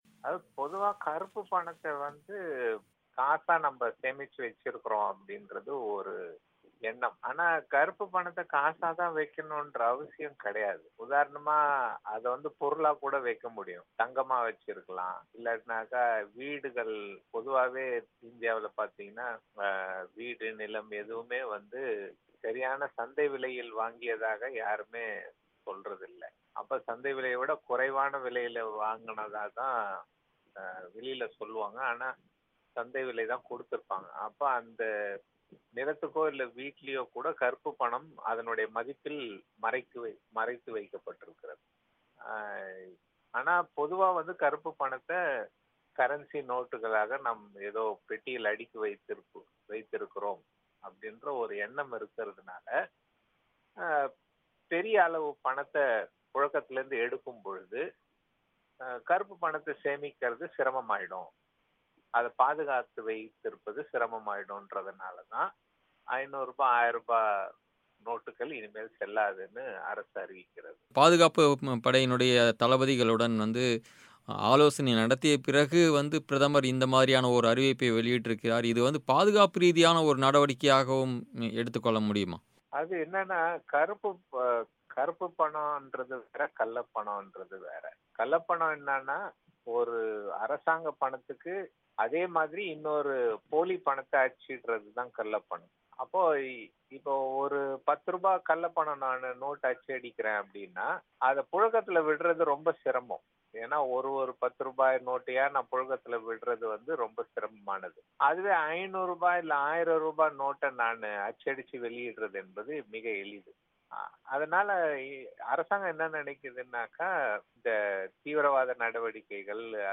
நள்ளிரவு முதல் 500 மற்றும் 1000 ரூபாய் நோட்டுகள் செல்லாது என்ற அறிவிப்பு ஏற்படுத்தியுள்ள தாக்கம் குறித்த பேட்டி